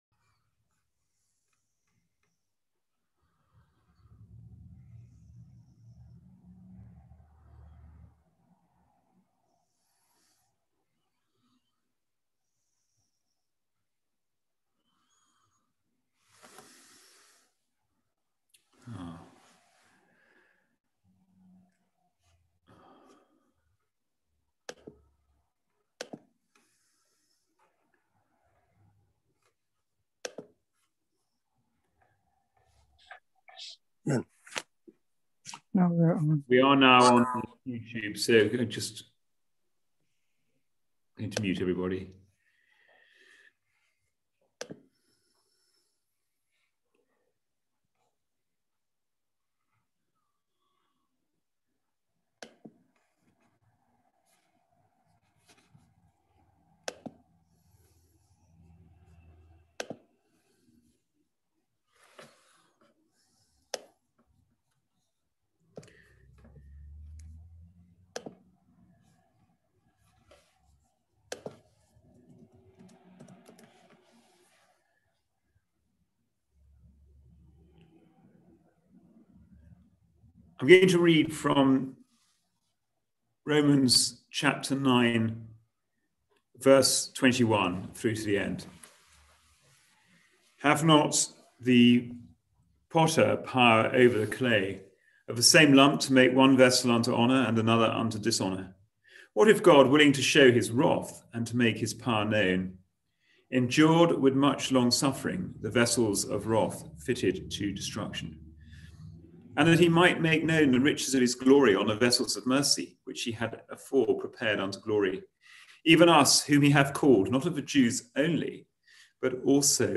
Passage: Romans 9:21-33 Service Type: Wednesday Bible Study